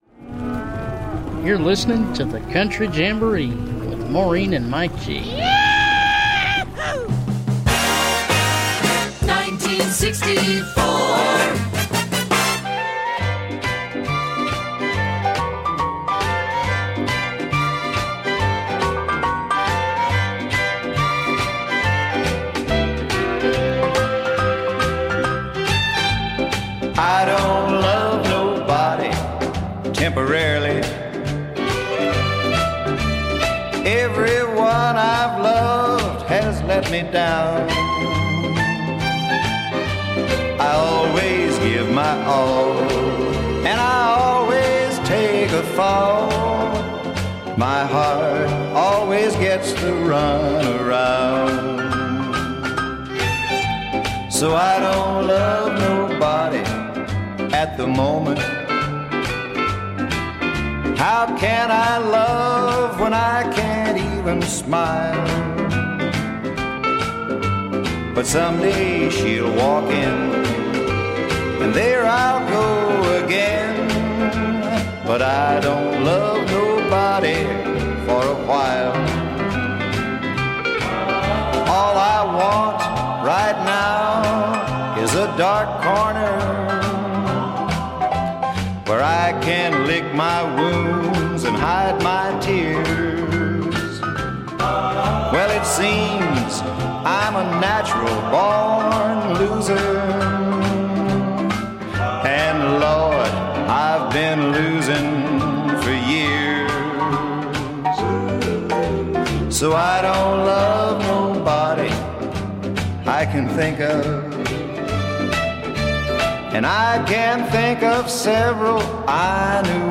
Featuring yesterday's and today's classic country and bluegrass.
Genre : Country